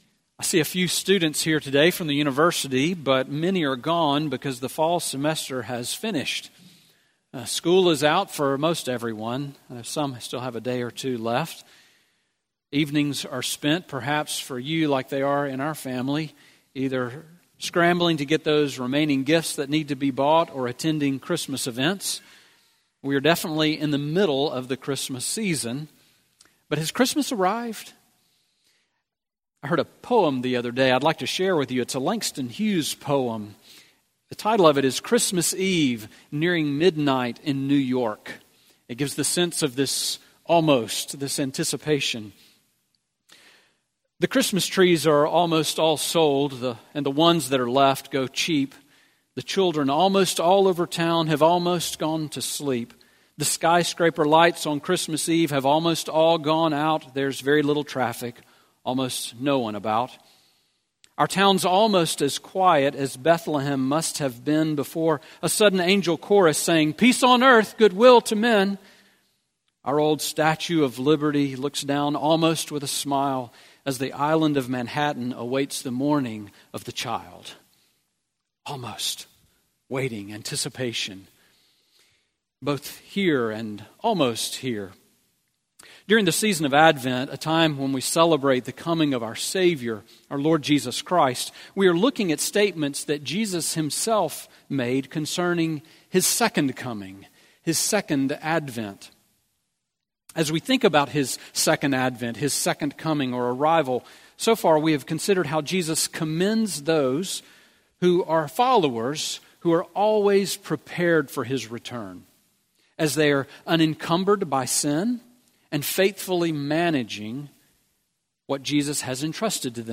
Sermon on Luke 17:20-37 from December 17